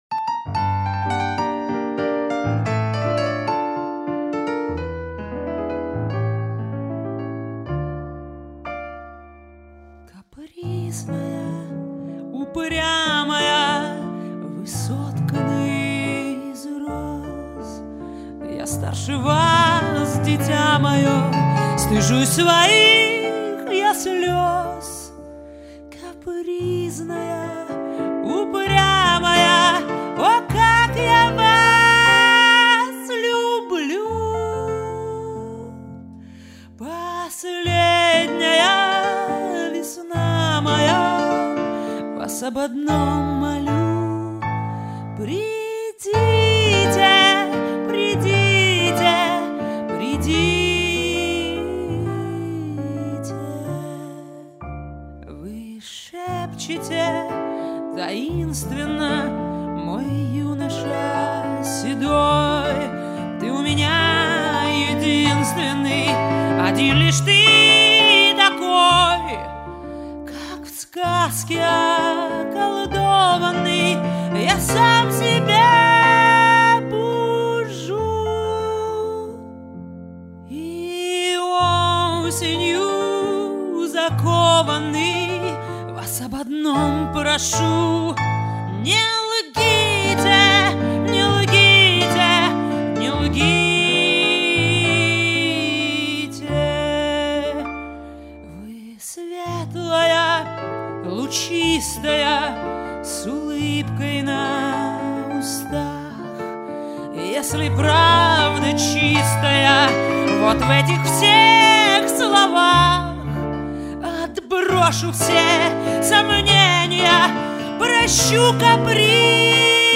вокал,бубен
рояль
скрипка,гитара,вокал
саксафон